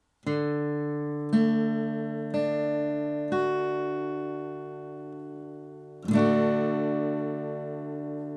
D-Dur (Offen)
D-Dur-Akkord, Gitarre
D-Dur.mp3